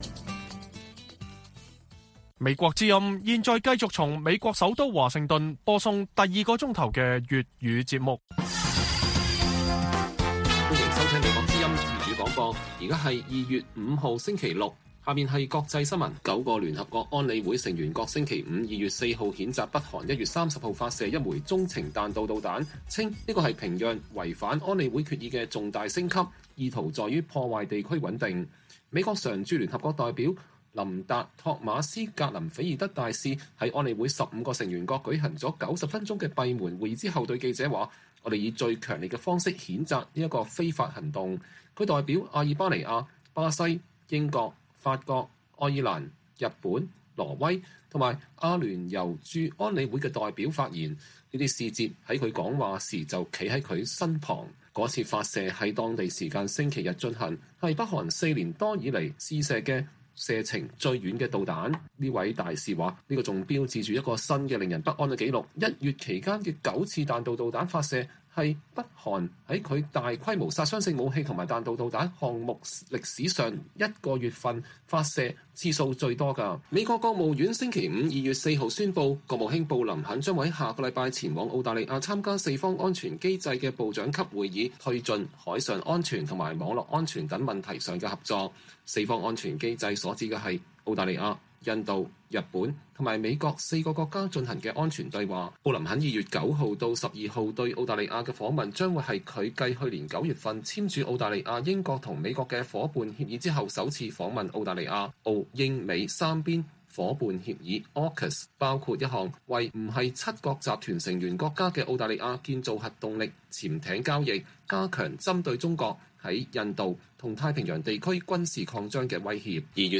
粵語新聞 晚上10-11點 : 恐懼加劇之際 聯合國對北韓新的制裁陷入僵局